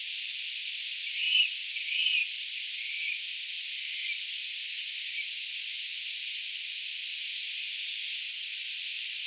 luhtakana hieman ääntelee
haarapääskysen pesäpaikan vierellä
vahan_luhtakana_aantelee_haarapaaskysen_pesapaikan_vierella.mp3